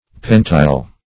pentile - definition of pentile - synonyms, pronunciation, spelling from Free Dictionary Search Result for " pentile" : The Collaborative International Dictionary of English v.0.48: Pentile \Pen"tile`\, n. See Pantile .